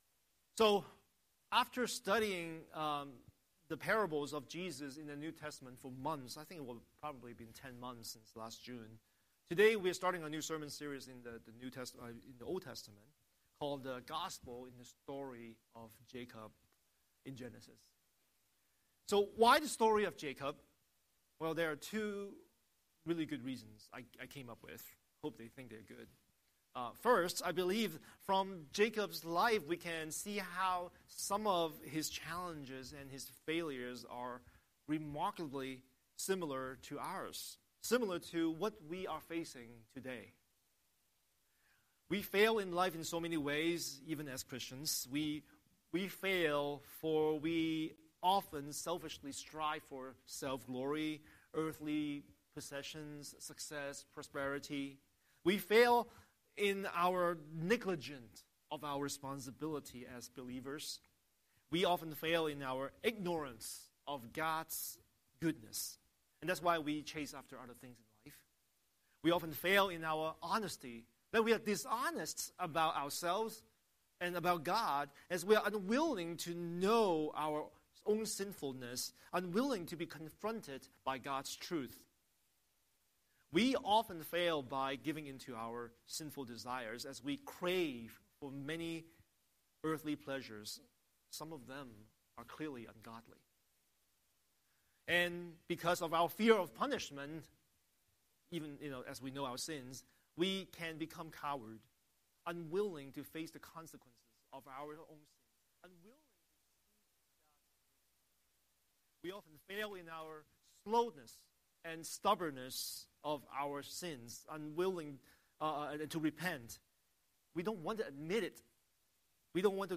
Scripture: Genesis 25:19–26 Series: Sunday Sermon